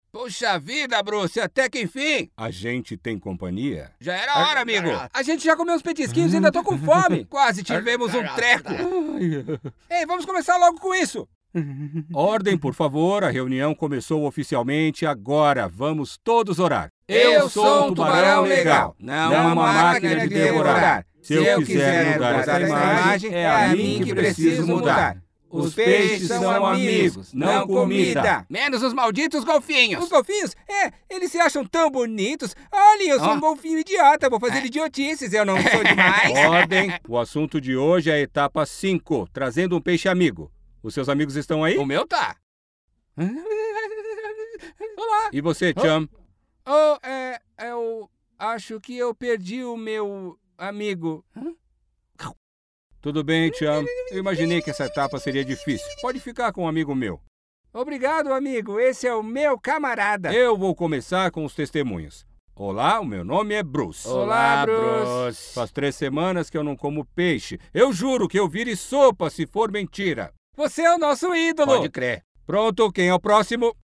Commercial, Natural, Reliable, Friendly, Corporate